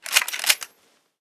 leverCock.ogg